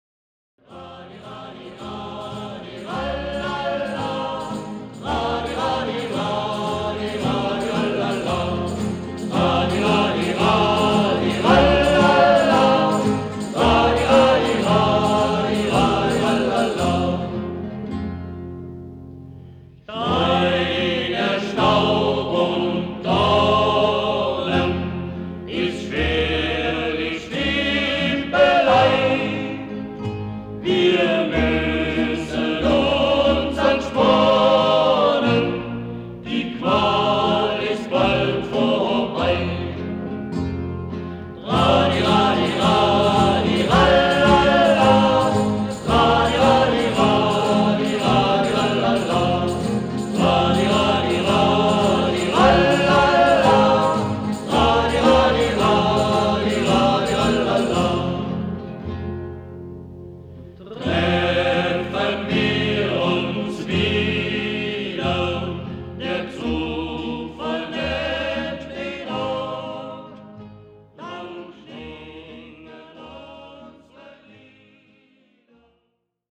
[ Urfassung 1977 - 4 Strophen ]